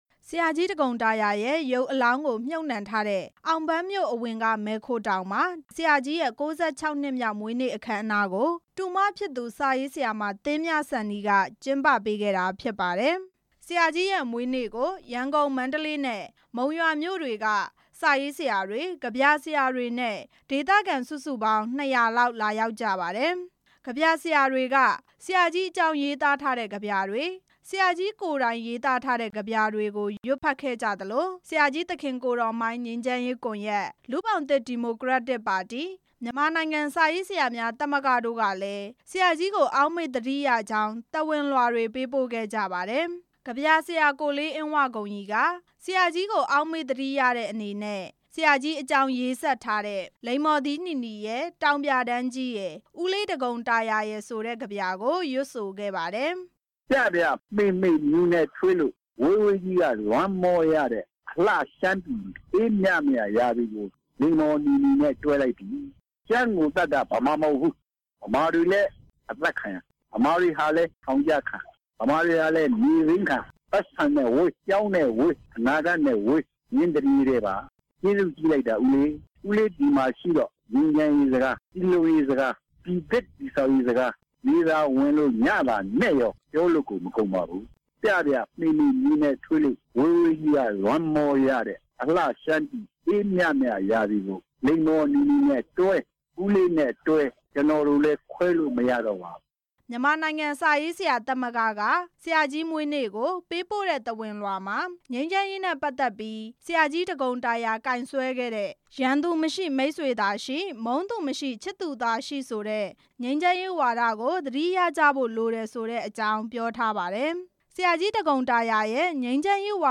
ကွယ်လွန်သွားပြီဖြစ်တဲ့ ဆရာကြီး ဒဂုန်တာရာရဲ့ မွေးနေ့အခမ်းအနားမှာ ဆရာကြီးကို အောက်မေ့သတိရတဲ့အနေနဲ့ ကဗျာဆရာတွေက ကဗျာတွေရွတ်ဖတ် ဂုဏ်ပြုခဲ့ကြပါတယ်။